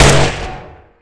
magrifle.ogg